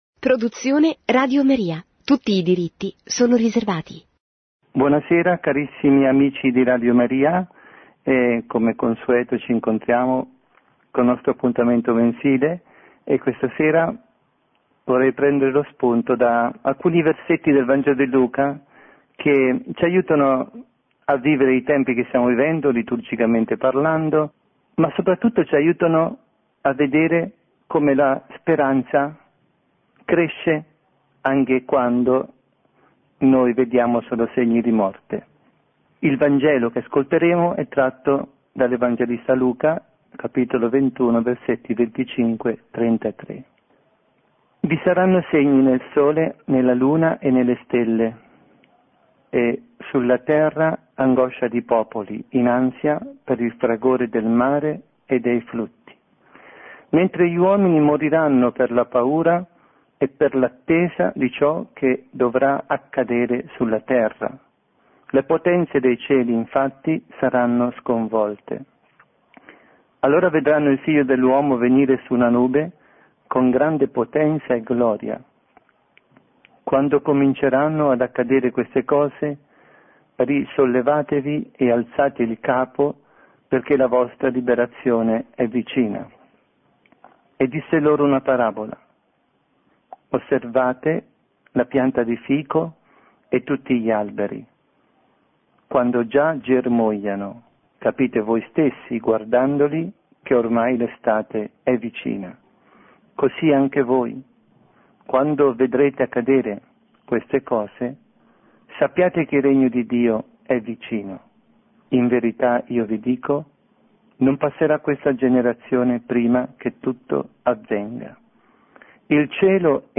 Catechesi